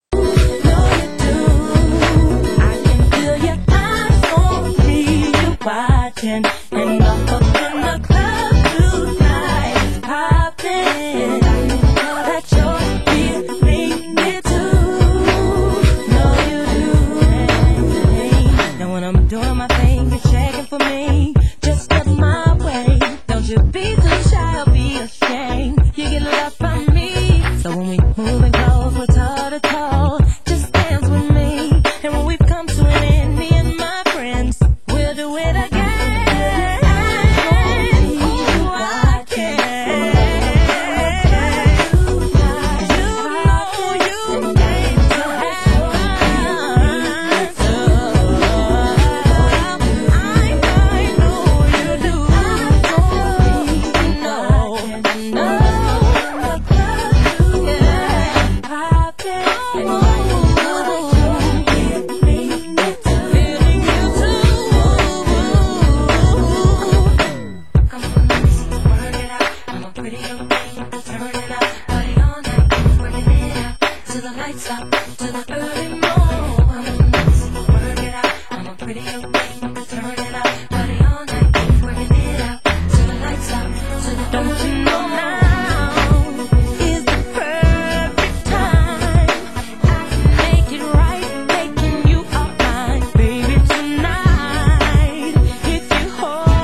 Genre Soul & Funk